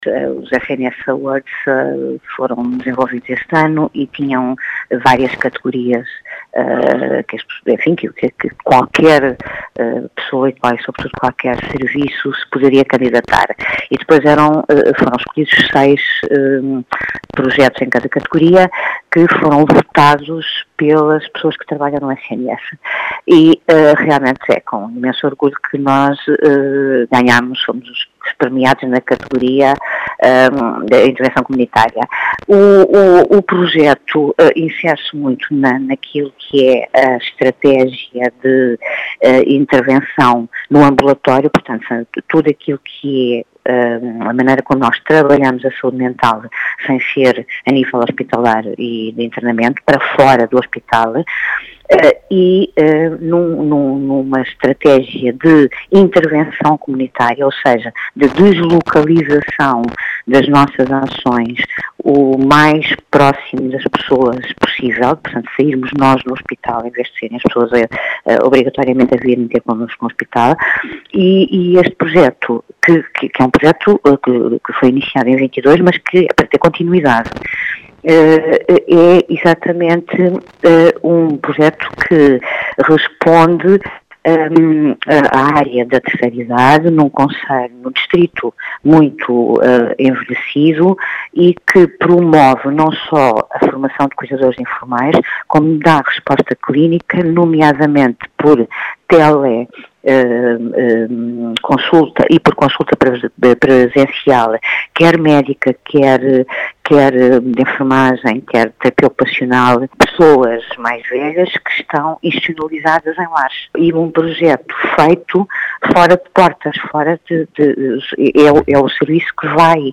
As explicações foram deixadas na Rádio Vidigueira